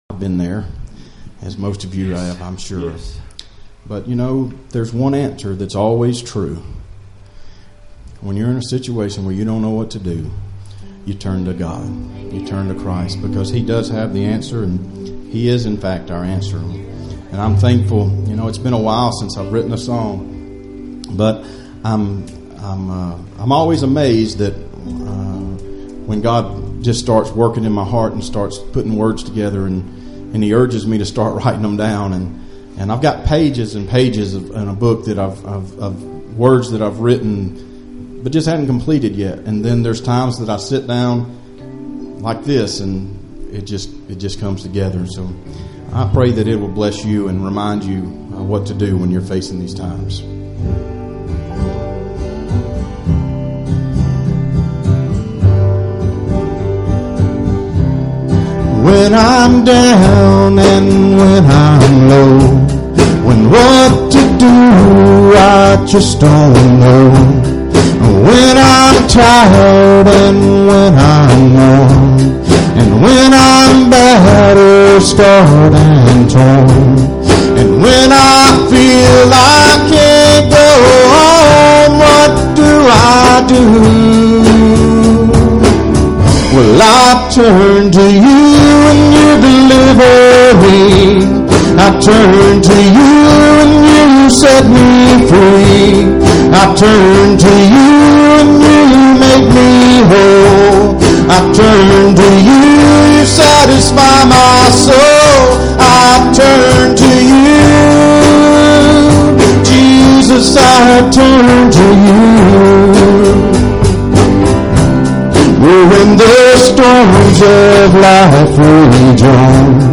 Passage: 1 John 5:11-15 Service Type: Sunday Evening Services Topics